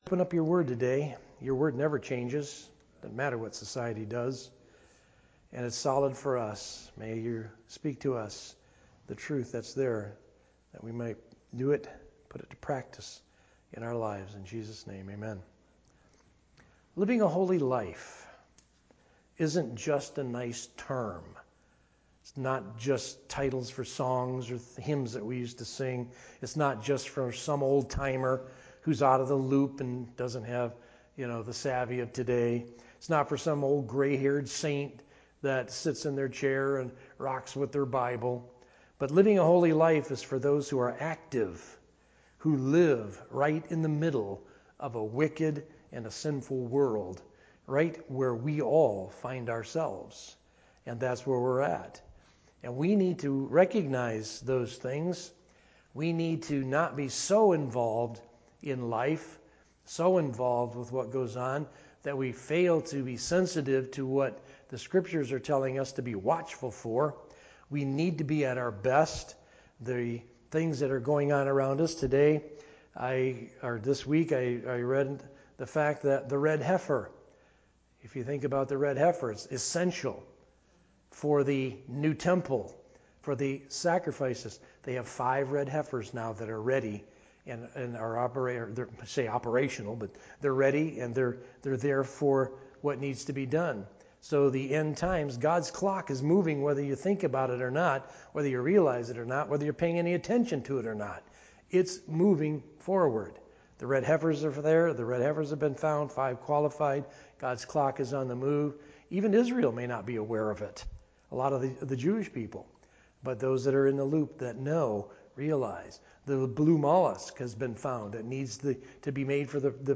Sermon Audio | FCCNB
Sermon